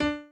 admin-fishpot/b_pianochord_v100l16o5d.ogg at main